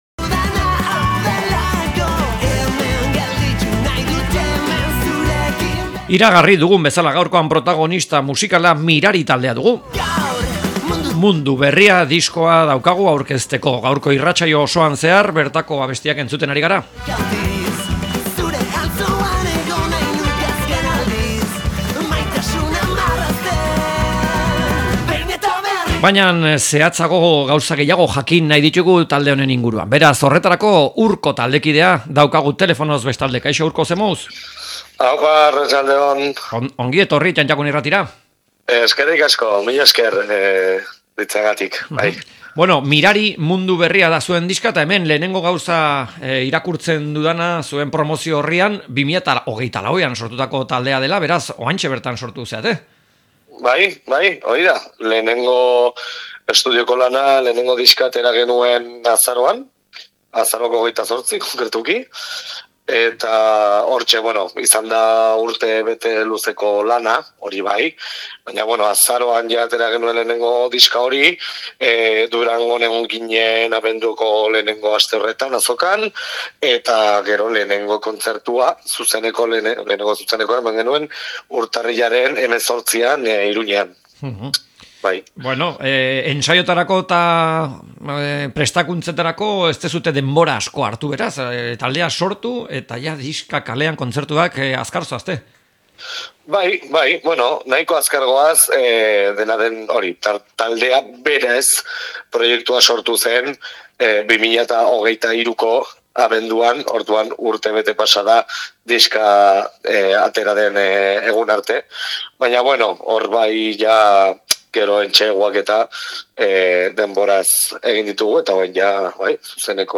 Mirari taldeari elkarrizketa